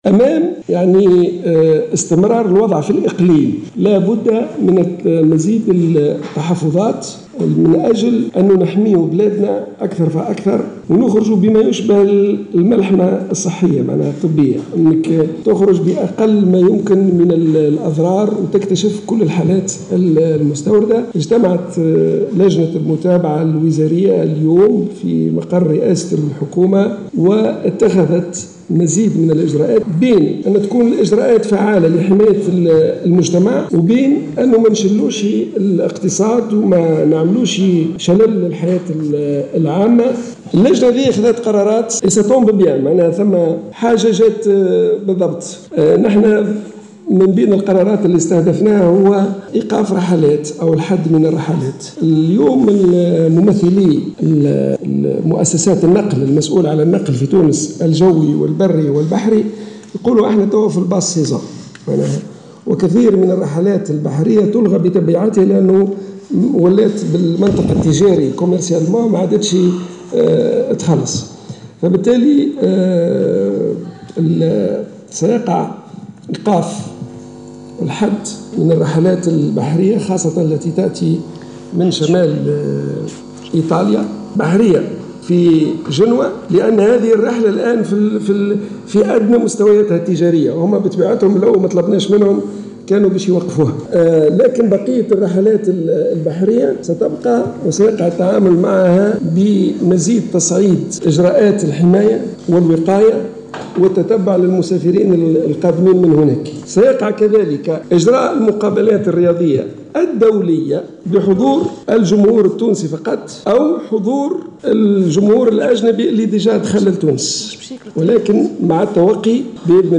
وأكد المكي، في ندوة صحفية عقدها مساء اليوم الأربعاء في مقر رئاسة الحكومة، عقب اجتماع لجنة المتابعة الوزارية، أن التحاليل المخبرية المجراة أمس واليوم، على المشتبه في إصابتهم بفيروس كورونا المستجد، كانت جميعا سلبية.